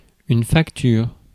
Ääntäminen
Synonyymit note ticket Ääntäminen France: IPA: /fak.tyʁ/ Haettu sana löytyi näillä lähdekielillä: ranska Käännös Substantiivit 1. arve Suku: f .